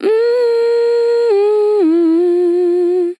Categories: Vocals Tags: DISCO VIBES, dry, english, female, fill, MMMMM, sample